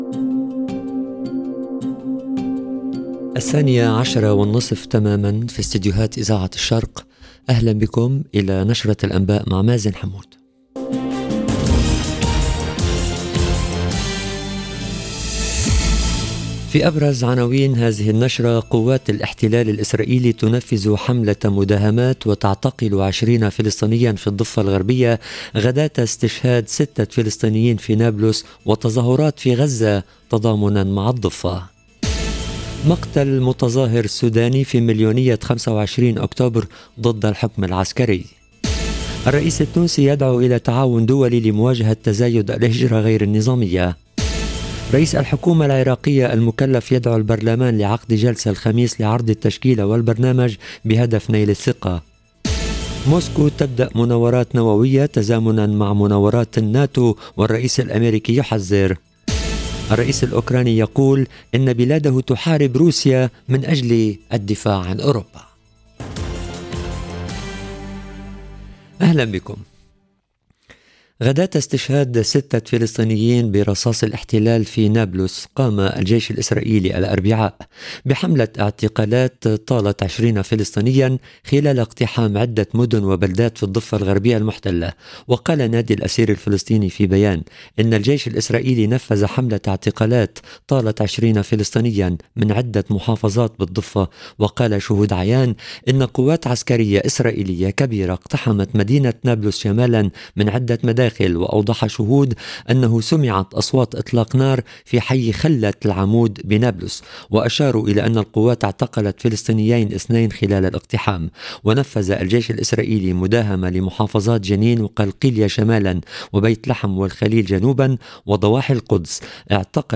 LE JOURNAL EN LANGUE ARABE DE MIDI 30 DU 26/10/22